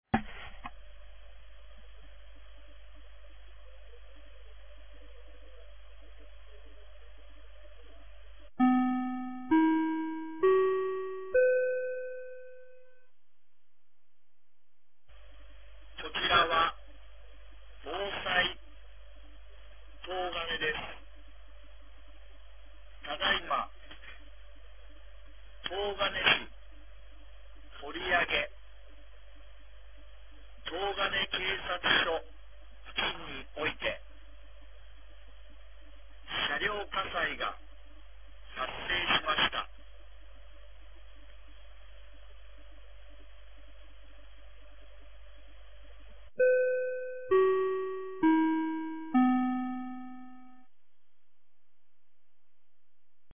2025年02月08日 18時51分に、東金市より防災行政無線の放送を行いました。